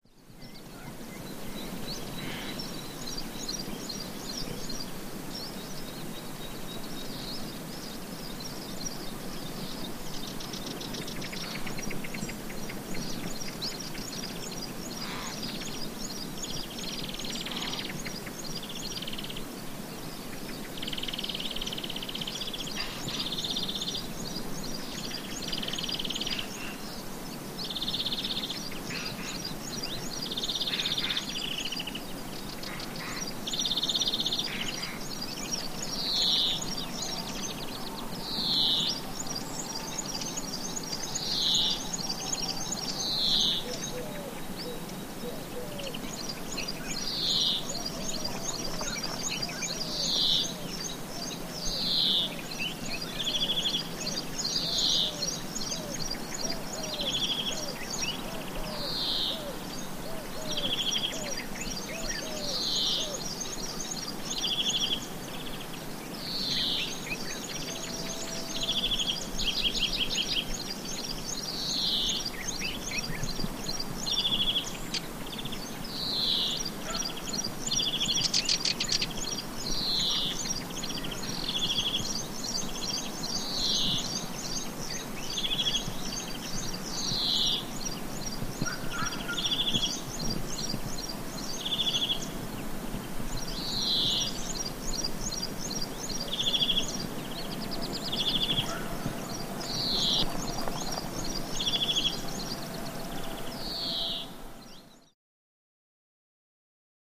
Israel Biblical Reserve, Early Morning Exterior Bird Ambience Near Pond